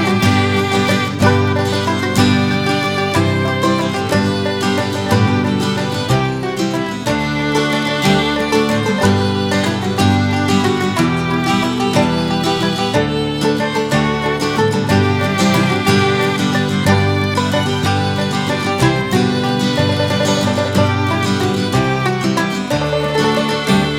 no Backing Vocals Irish 3:20 Buy £1.50